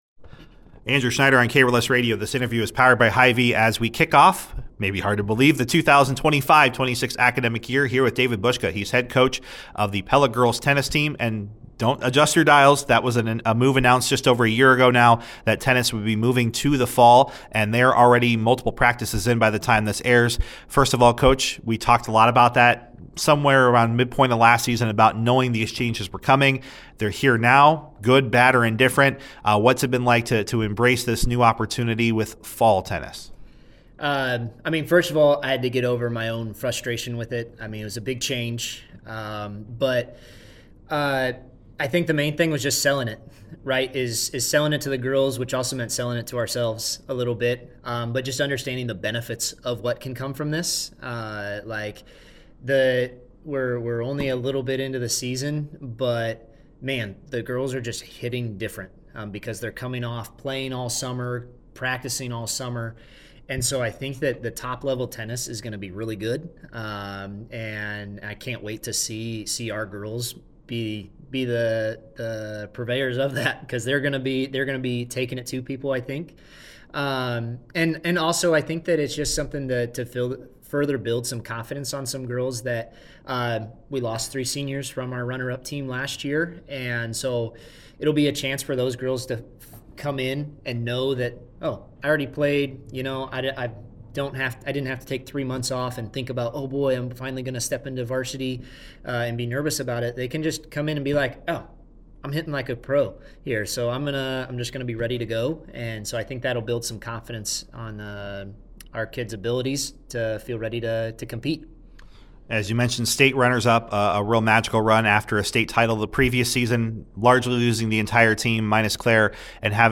Season preview interview